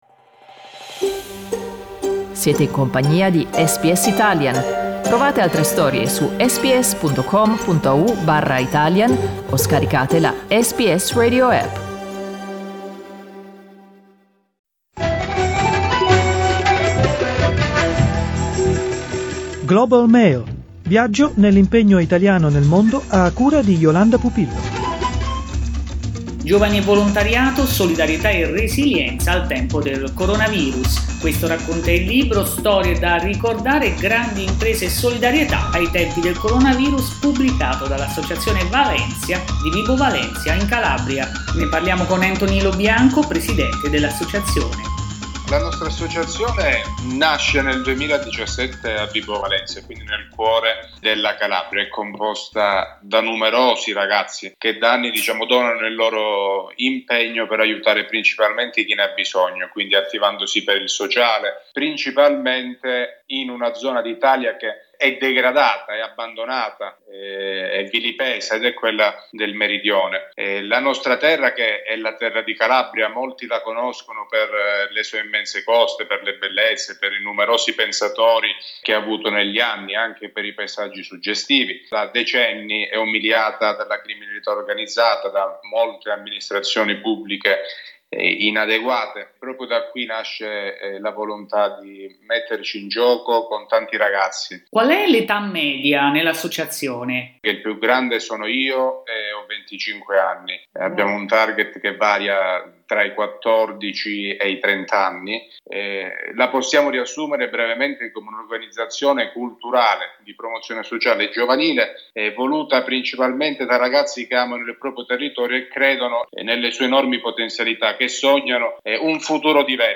Ascolta l'intervista: LISTEN TO Grandi imprese e solidarietà ai tempi del coronavirus SBS Italian 08:10 Italian Le persone in Australia devono stare ad almeno 1,5 metri di distanza dagli altri.